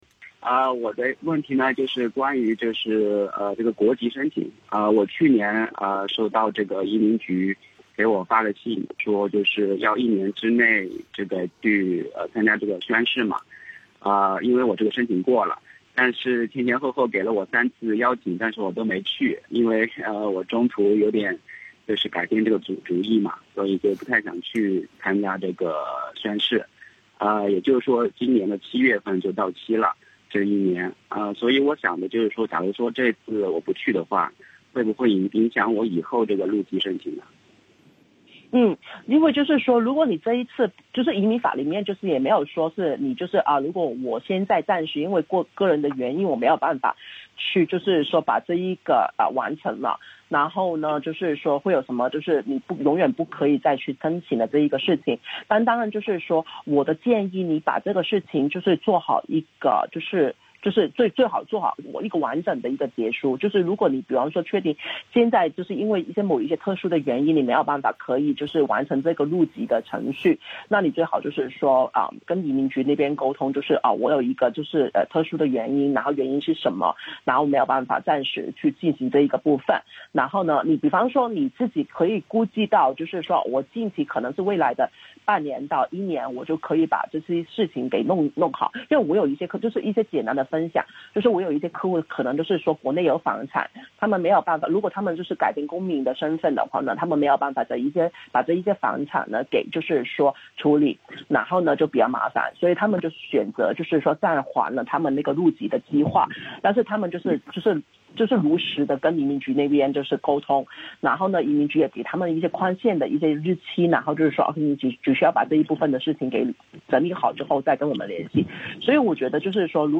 听众热线